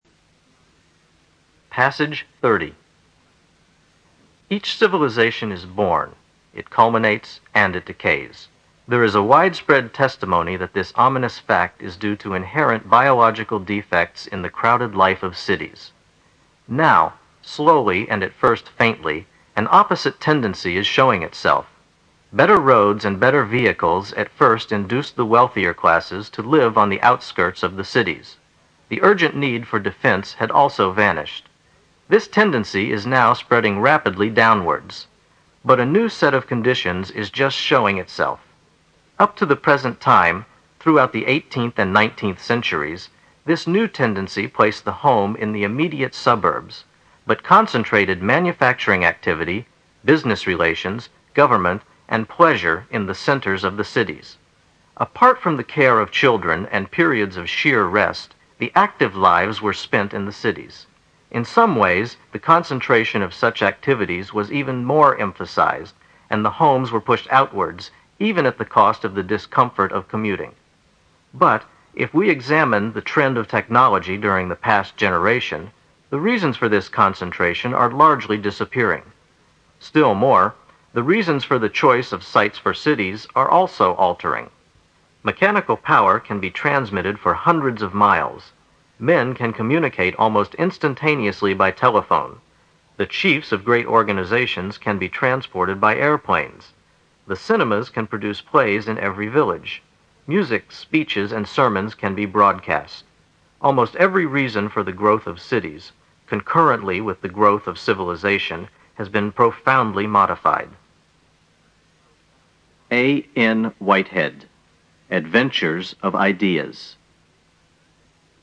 新概念英语85年上外美音版第四册 第30课 听力文件下载—在线英语听力室